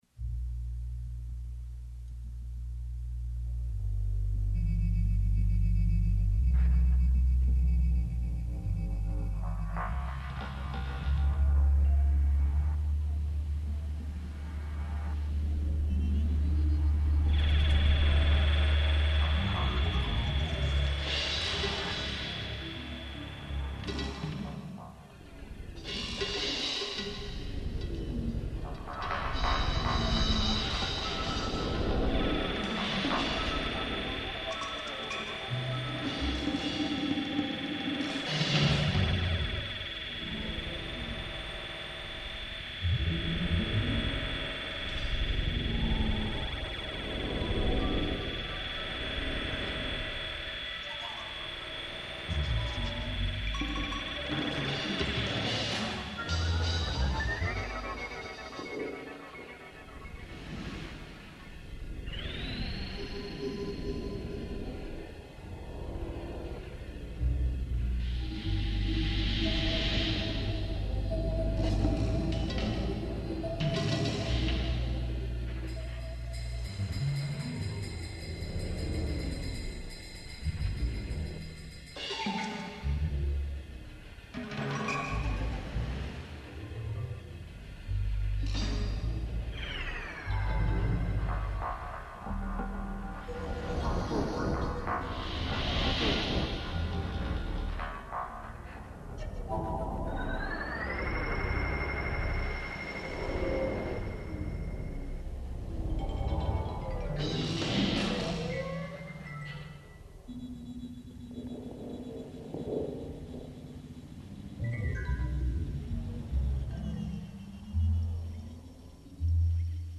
digitale Improvisation